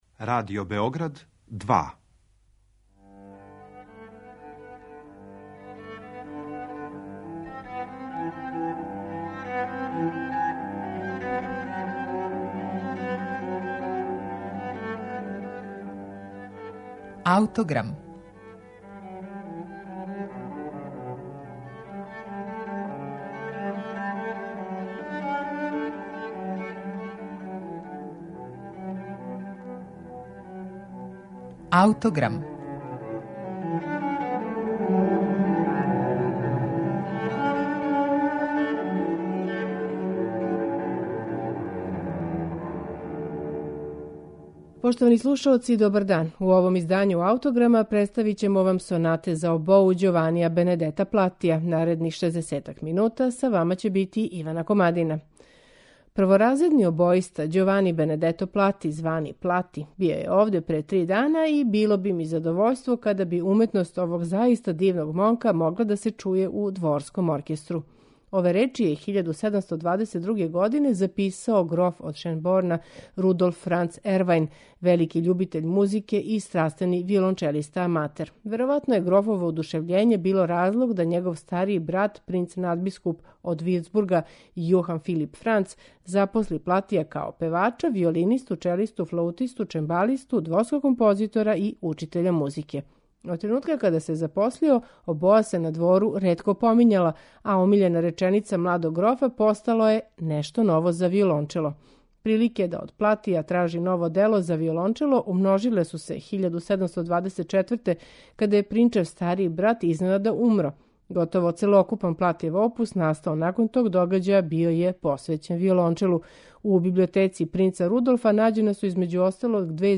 Ђовани Бенедето Плати: Сонате за обоу
У данашњем Аутограму , представићемо Платијеве сонате за обоу, у интепретацији чланова ансамбла Кордиа, који свирају на оригиналним инстурментима 18. века.